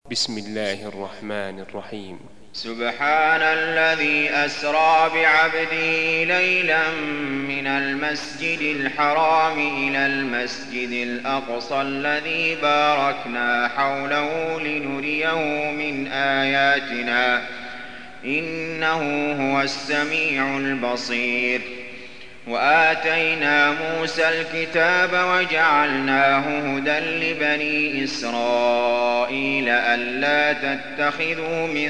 المكان: المسجد الحرام الشيخ: علي جابر رحمه الله علي جابر رحمه الله الإسراء